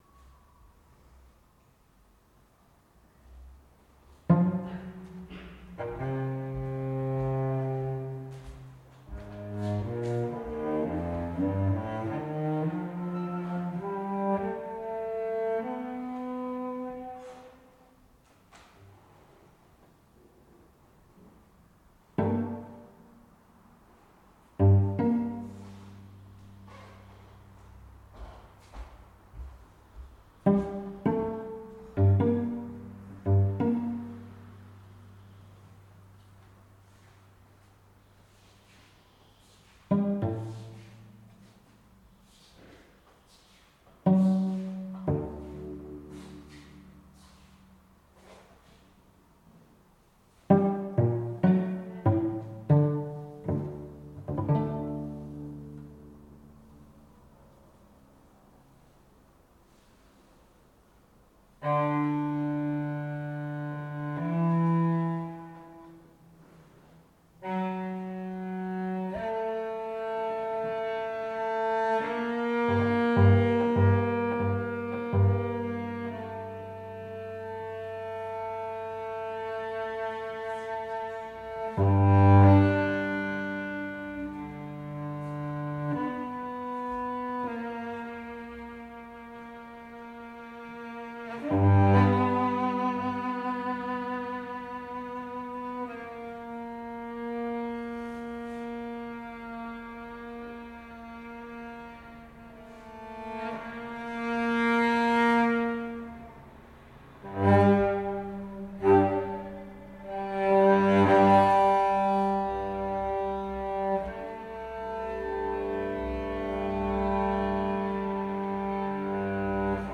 violoncelle
composition instantanée au violoncelle
dans l’acoustique naturelle du studio Chandon